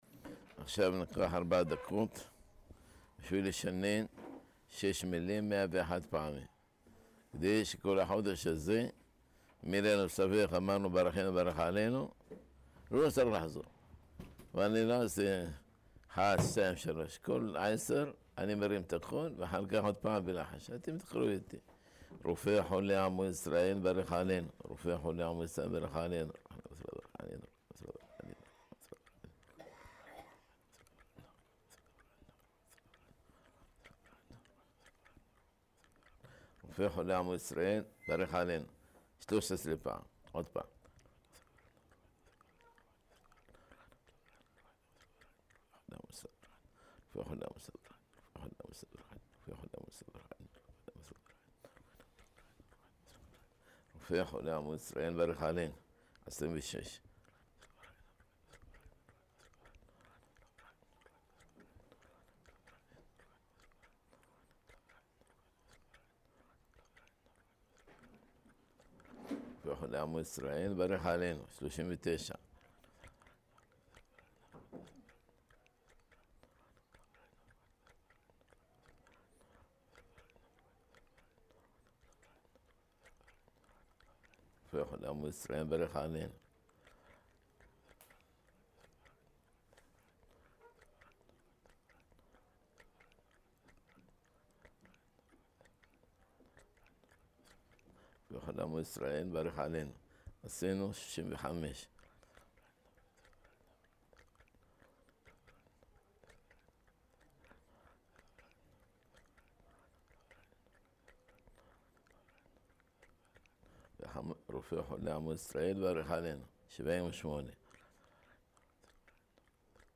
(השיעור נמסר לפני ערבית של ליל ז' בחשון) | חזרה 101 פעמים על המלים 'רופא חולי עמו ישראל ברך עלינו' | המקור לחזרה מאה ואחת פעמים | מדוע לא מספיק תשעים פעם כמו שכתב מרן?